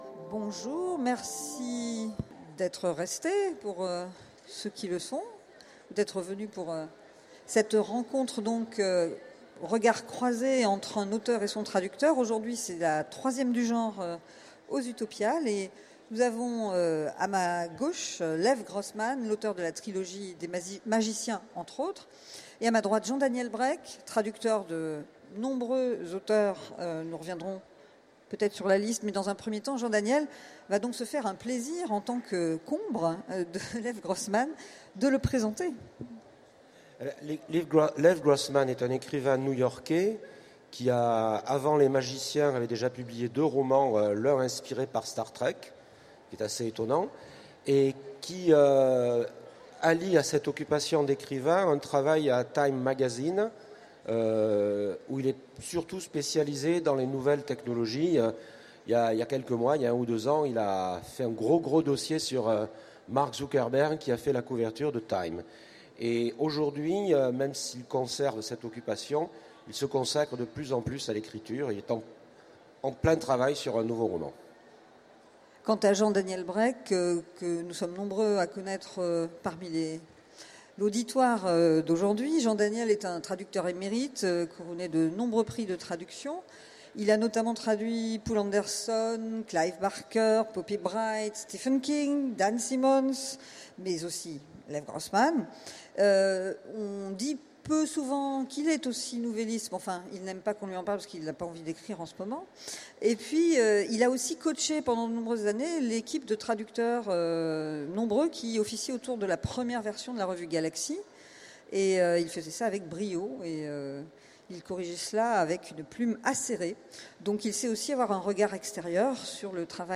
Utopiales 2016 : Conférence L’auteur et son ombre
Conférence L’auteur et son ombre